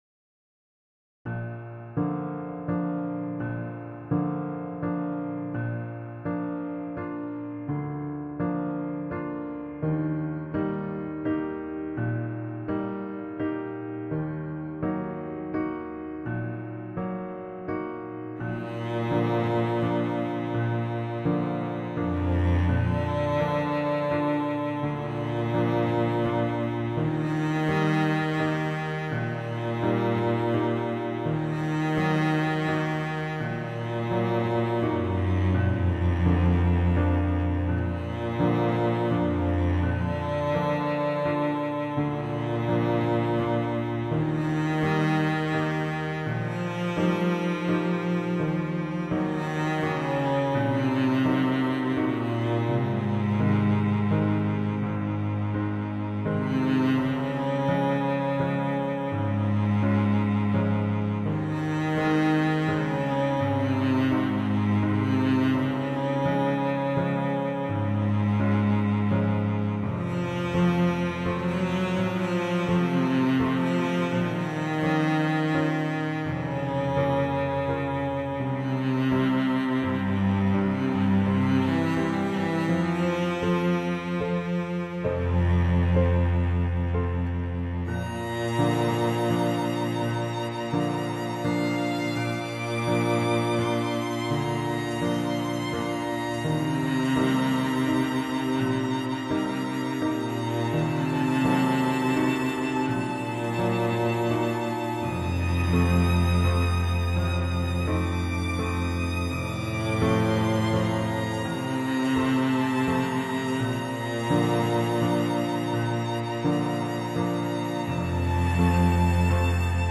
So here's something different - a purely instrumental track!